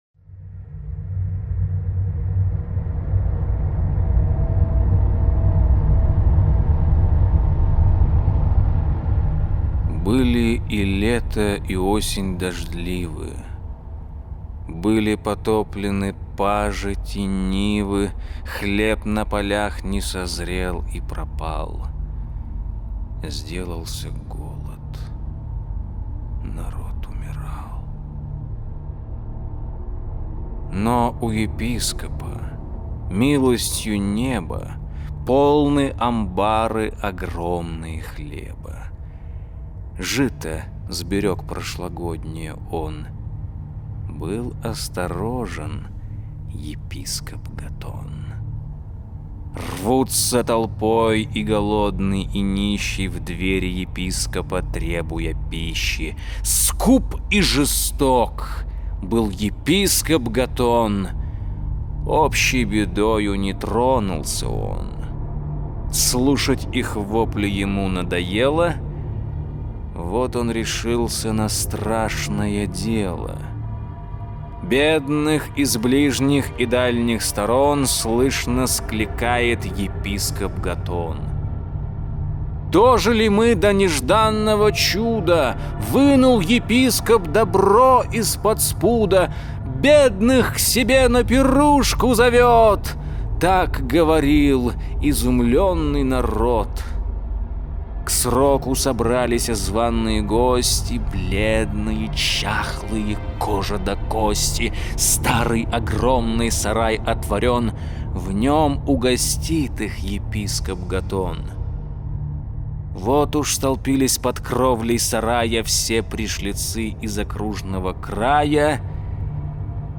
Суд Божий над епископом - аудио стих Саути - слушать онлайн